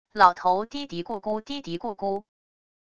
老头嘀嘀咕咕嘀嘀咕咕wav音频